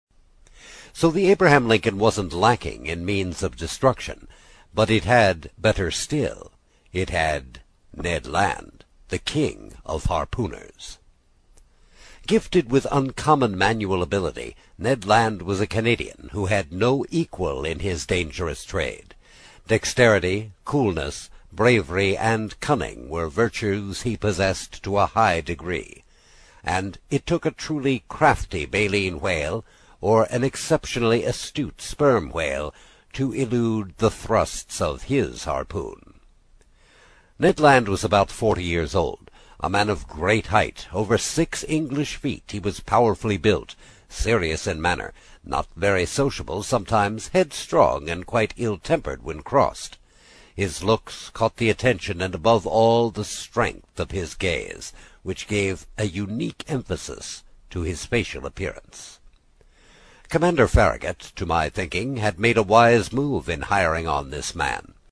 英语听书《海底两万里》第35期 第4章 尼德兰(4) 听力文件下载—在线英语听力室
在线英语听力室英语听书《海底两万里》第35期 第4章 尼德兰(4)的听力文件下载,《海底两万里》中英双语有声读物附MP3下载